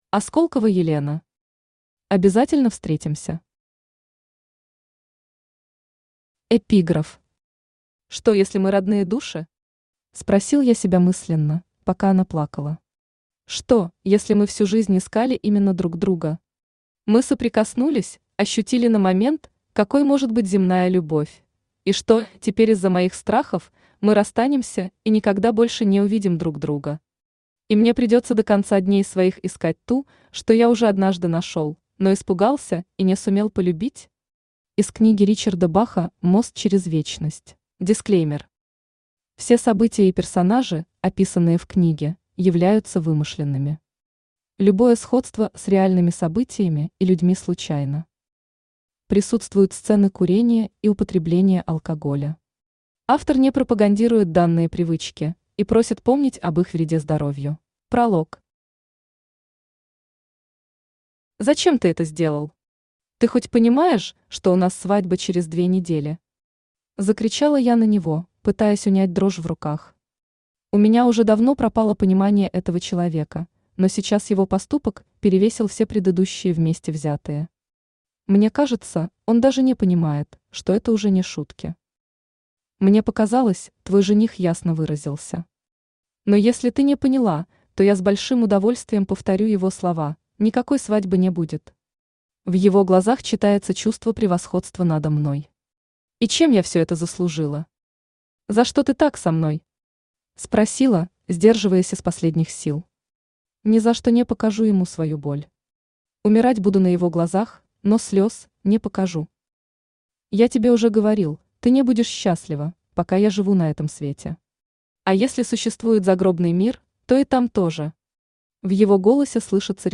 Аудиокнига Обязательно встретимся | Библиотека аудиокниг
Aудиокнига Обязательно встретимся Автор Осколкова Елена Читает аудиокнигу Авточтец ЛитРес.